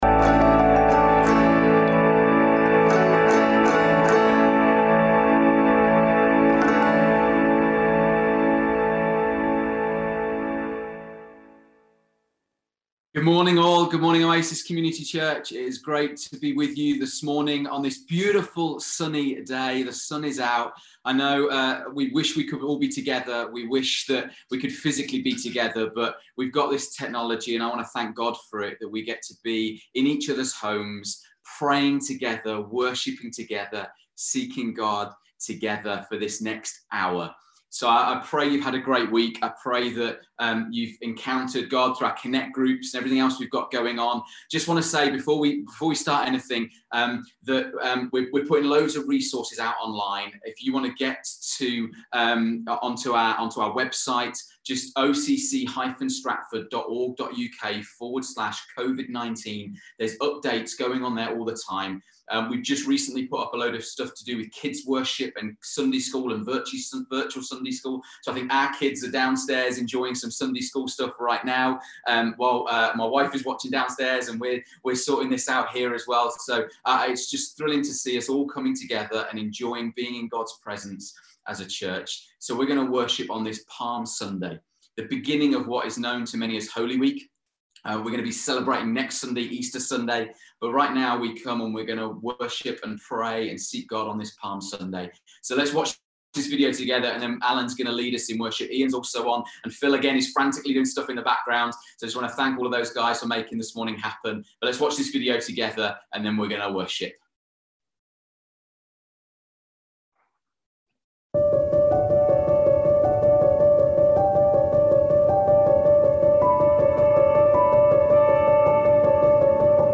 Listen again to our hour of Prayer & Worship from Sunday 5th April 2020